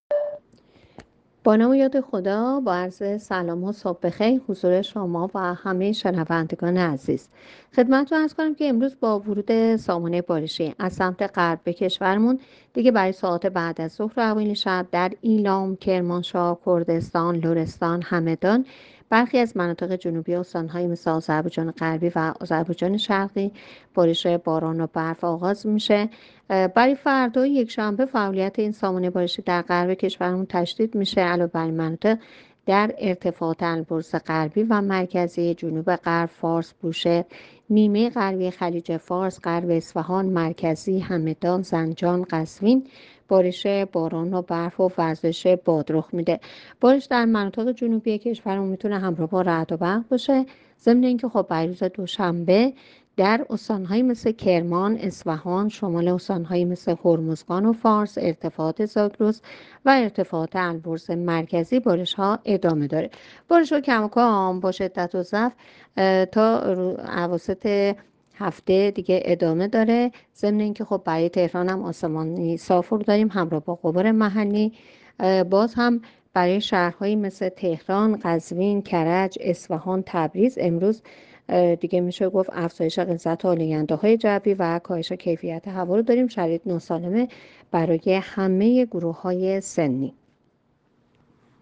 گزارش رادیو اینترنتی پایگاه‌ خبری از آخرین وضعیت آب‌وهوای ۲۲ دی؛